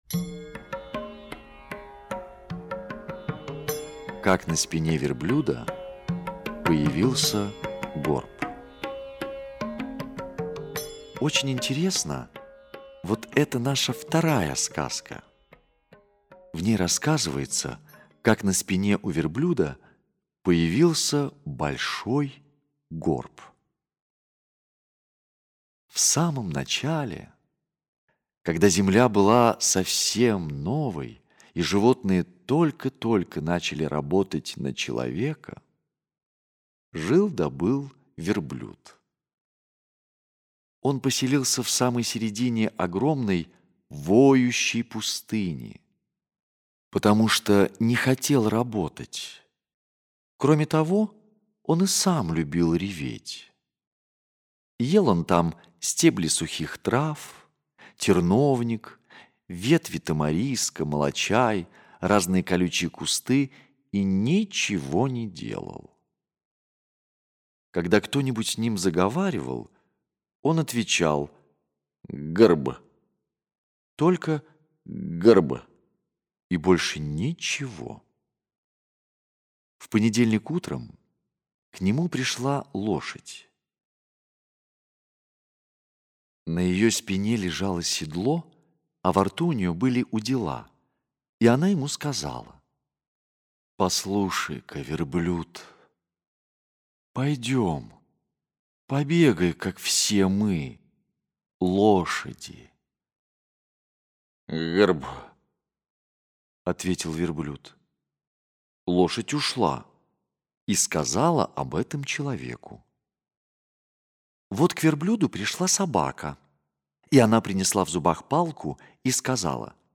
Аудиосказка «Как на спине верблюда появился горб»